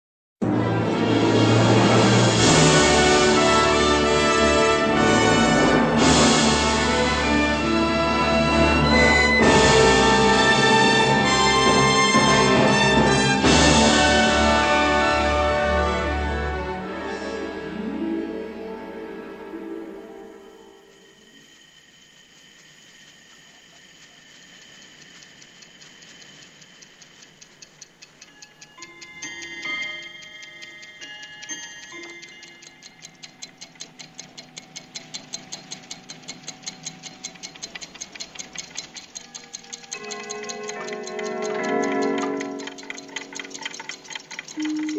The complete symphonic score is presented in stereo